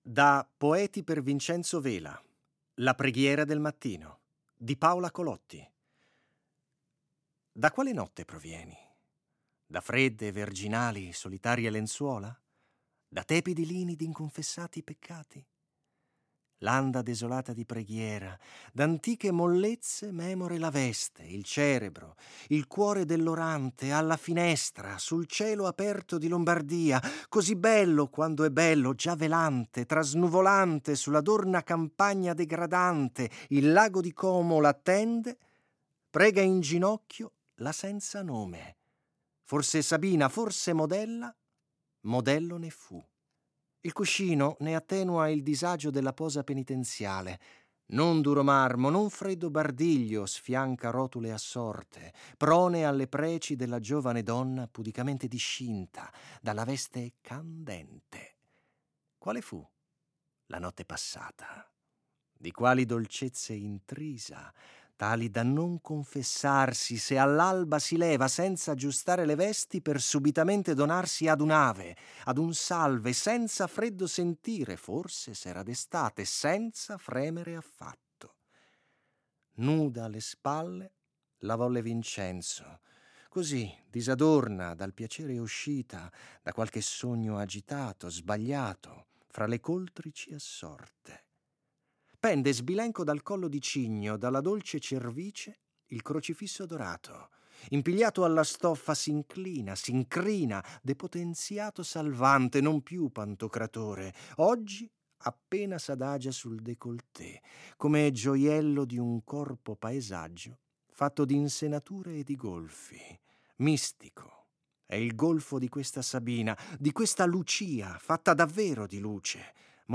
Ora anche «Colpo di poesia» dedica uno spazio alla pubblicazione, con una serie letture degli stessi interpreti